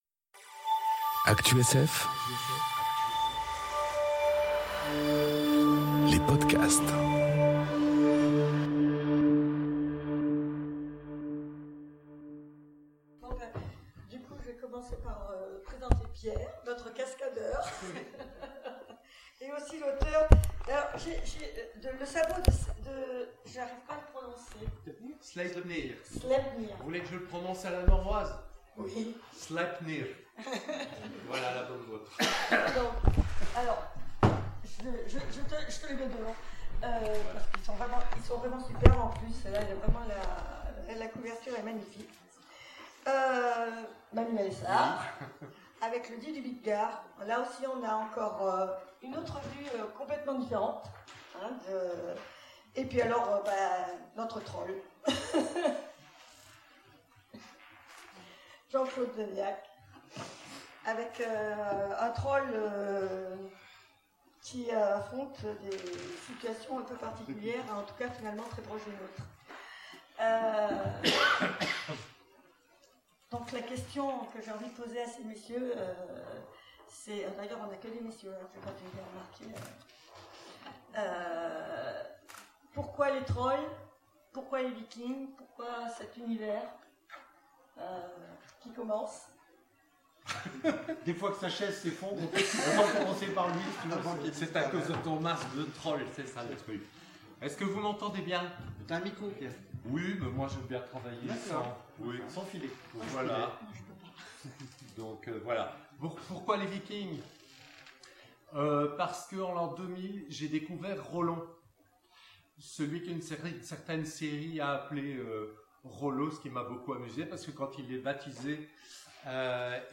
Aventuriales 2018 : Conférence Mythologie scandinave et Imaginaire